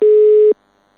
busy.mp3